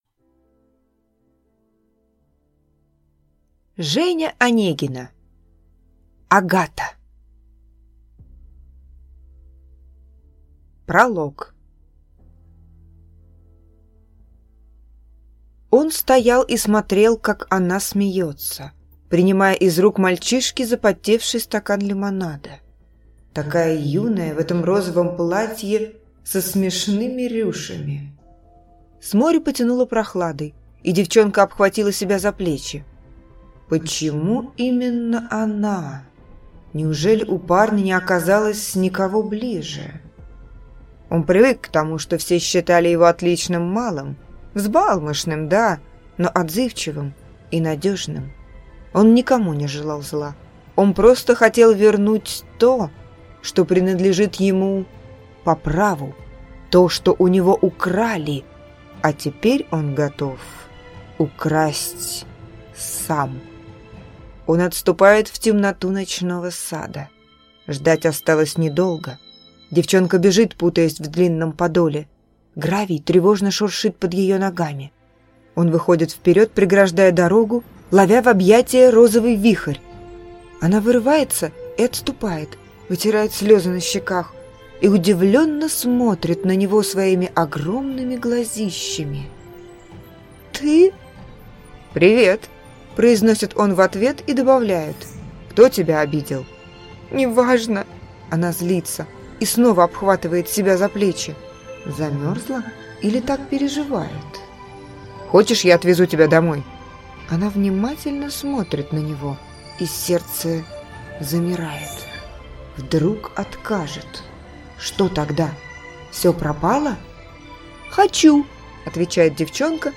Аудиокнига Агата | Библиотека аудиокниг